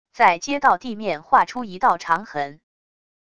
在街道地面划出一道长痕wav音频